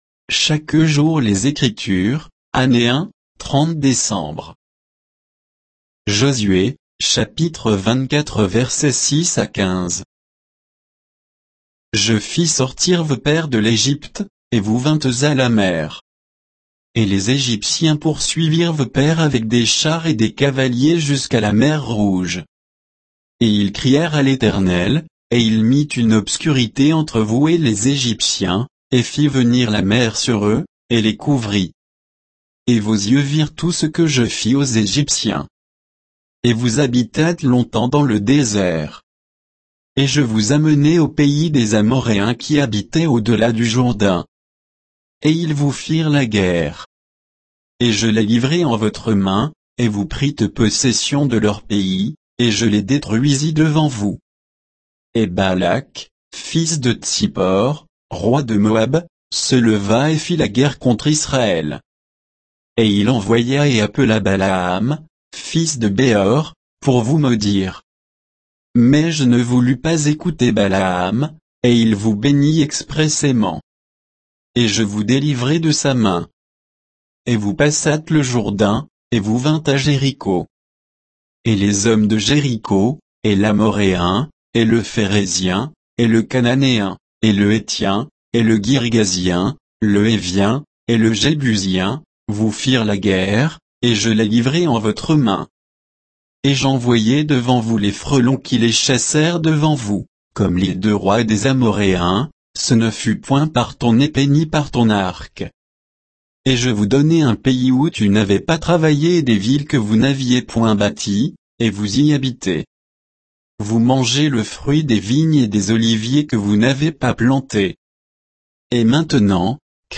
Méditation quoditienne de Chaque jour les Écritures sur Josué 24, 6 à 15